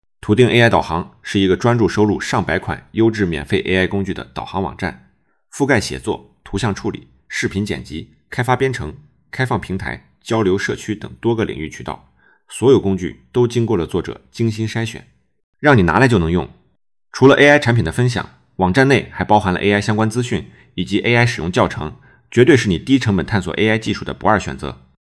克隆后你就可以使用它进行文本转语音，复刻还原度极高，几乎和我自己配音一样。
MiniMax Audio 克隆音频：